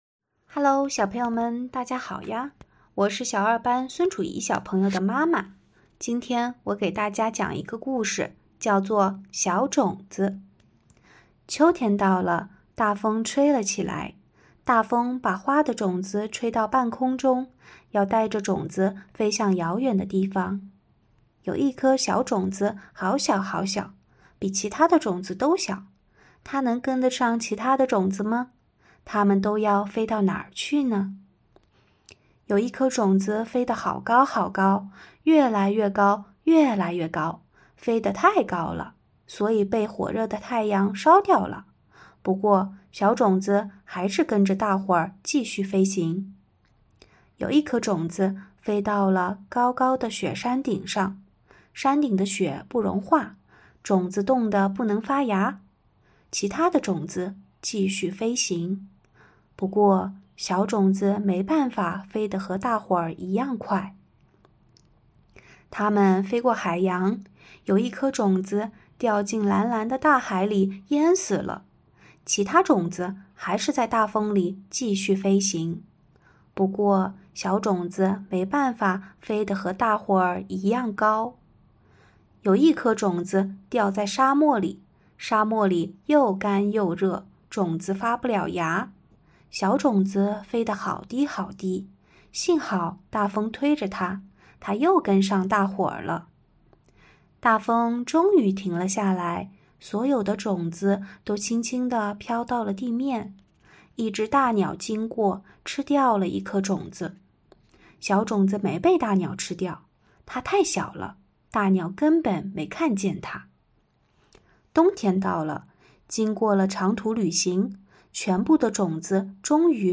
【灵动西幼】围裙妈妈讲故事--《小种子》